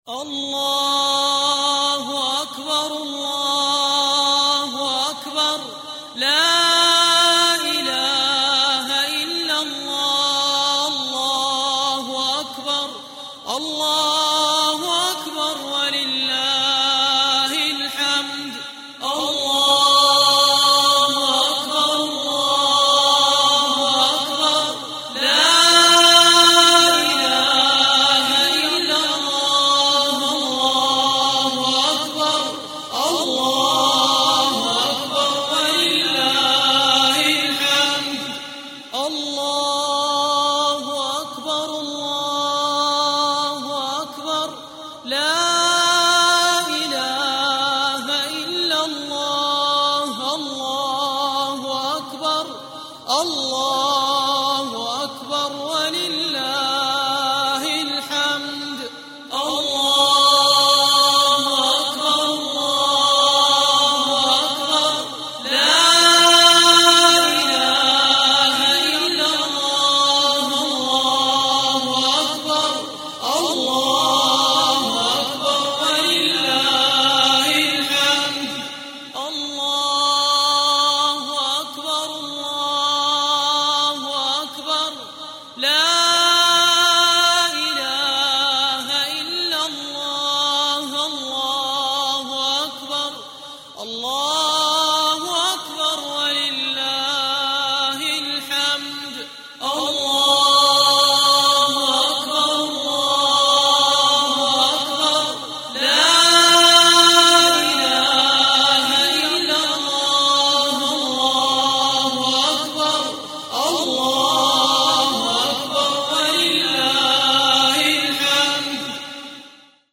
تكبيرات العيد mp3 تحميل مباشر تكبيرات العيد 12 من شيوخ الحرم